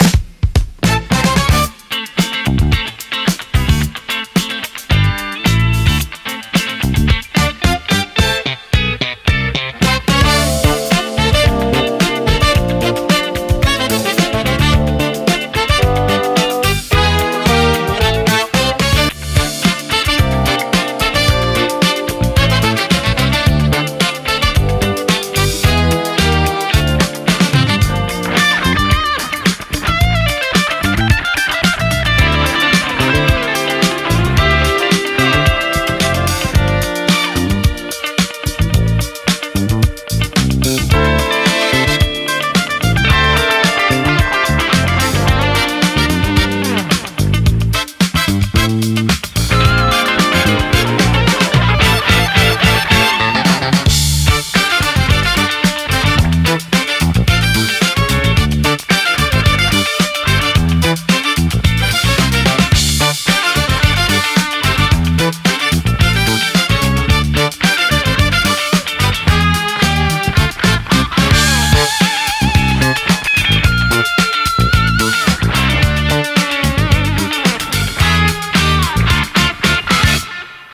Don't mind the suboptimal sound quality in some of the songs, most of them are intentionally not polished.
Looping VR experience music for work.
This was my first experiment with AI aided music creation using own base idea, melody, guitars,arrangement, mixing and mastering.
Maybe there is no answer but at least I would not be able to play such bass line myself so needed to mimic the style by humming.. and the song still took significant bit of manual work.